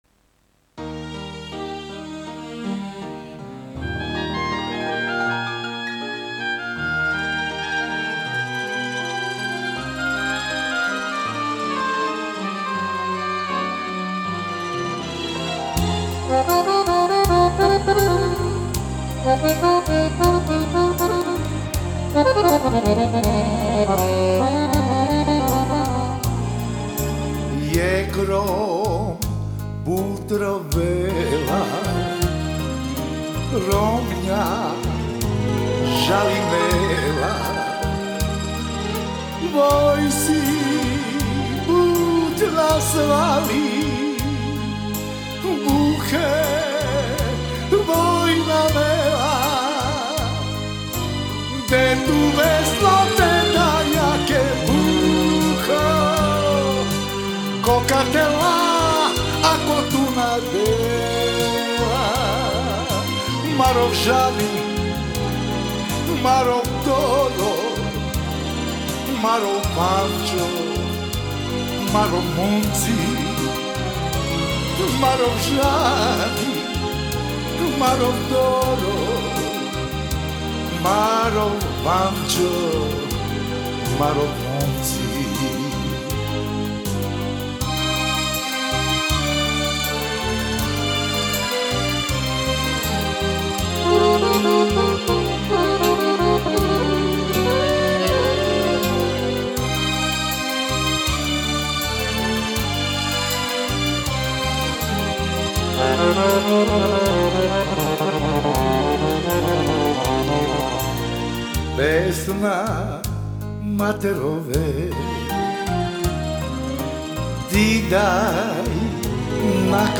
Genre: Gypsy Jazz, World, Ethnic, Folk Balkan